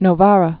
(nō-värə, -värä)